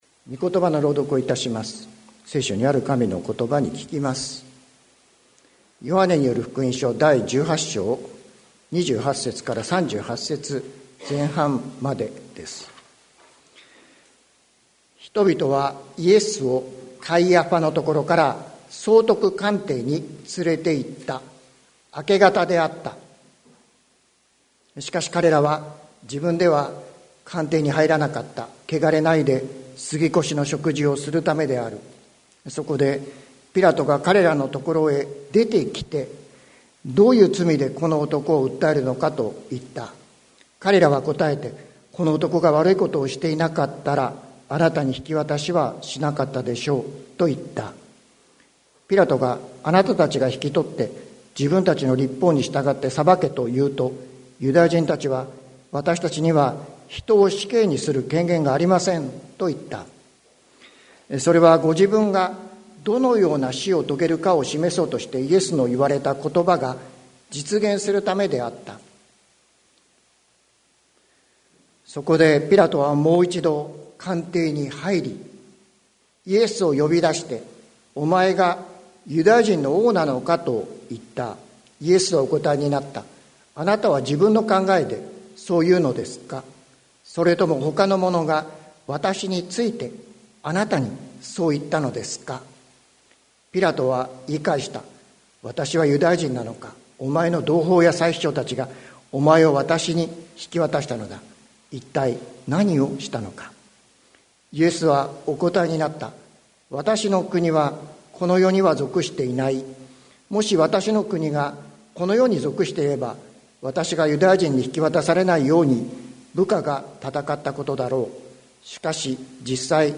2023年11月05日朝の礼拝「真理とは何か」関キリスト教会
説教アーカイブ。